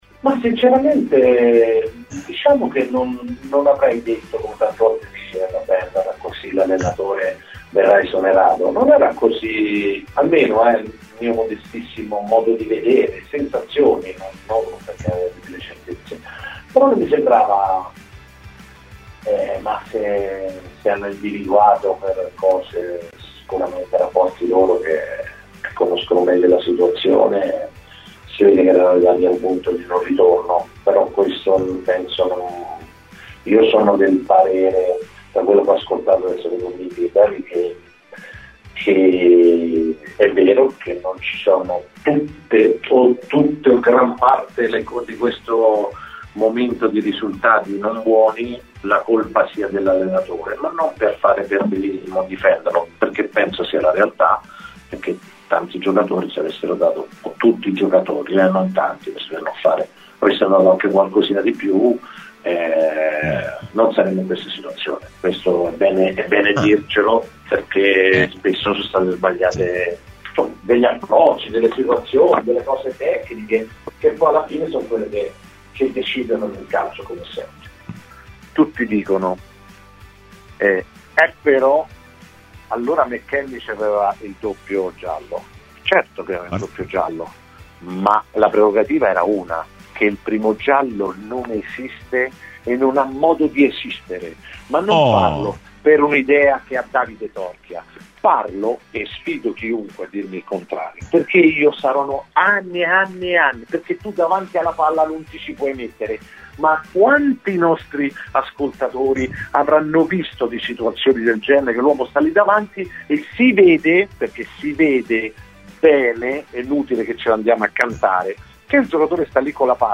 Queste le sue parole durante la trasmissione Rassegna Stramba.